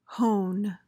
PRONUNCIATION: (hohn) MEANING: noun: A fine-grained stone or tool for sharpening blades.
hone.mp3